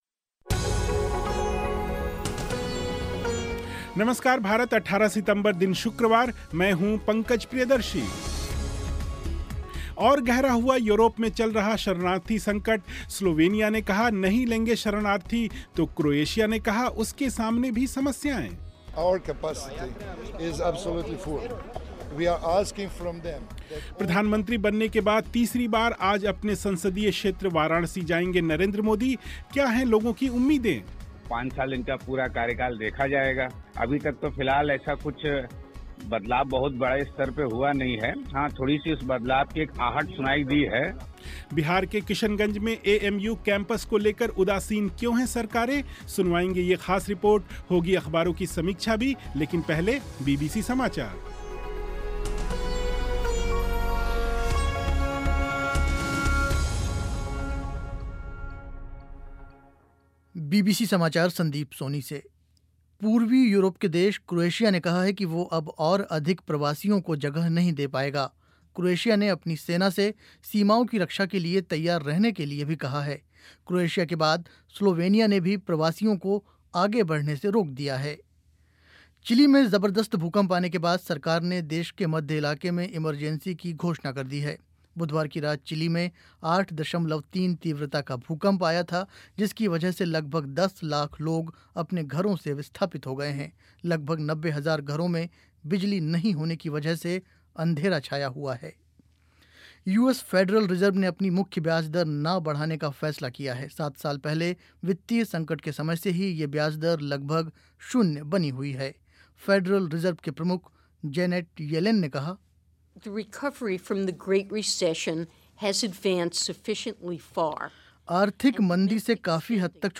सुनवाएँगे ये ख़ास रिपोर्ट